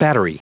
Prononciation du mot battery en anglais (fichier audio)
Prononciation du mot : battery